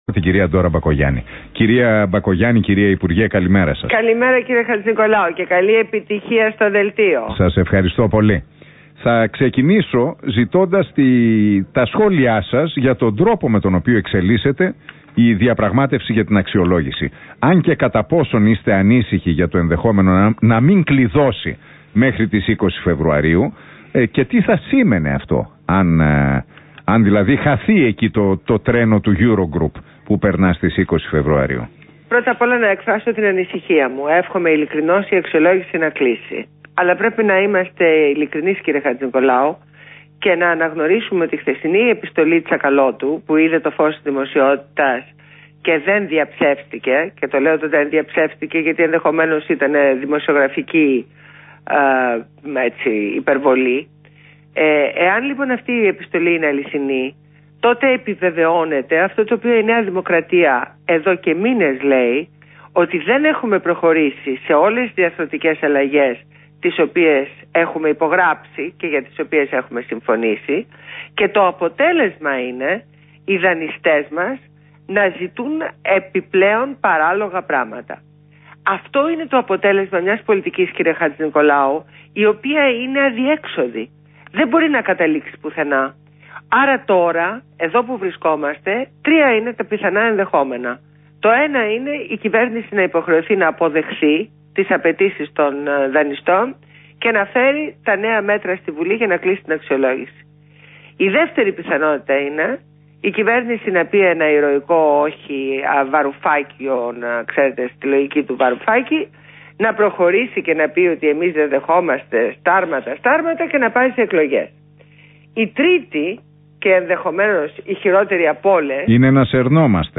Ακούστε τη συνέντευξη στο Real fm στο δημοσιογράφο Ν. Χατζηνικολάου.